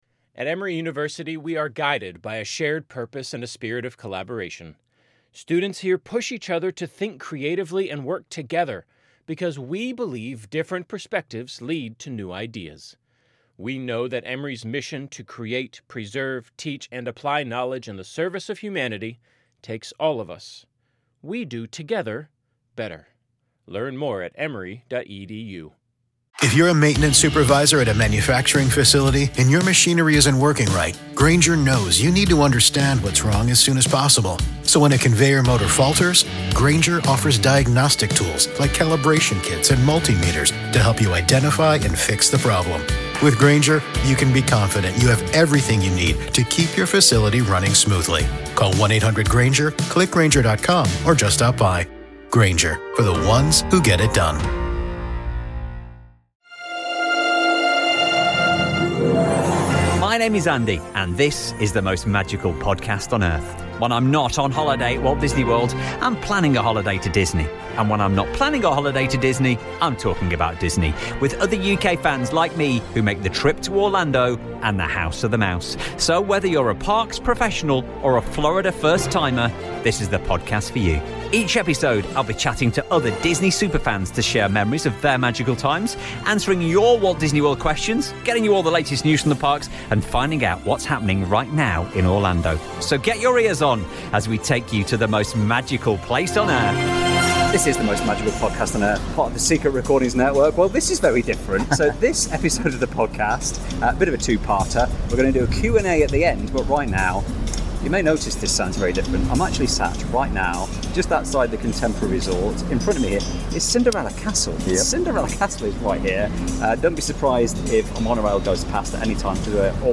recorded from Disney's Contemporary Resort with a view of the Magic Kingdom. We chat all things from wait times to new experiences.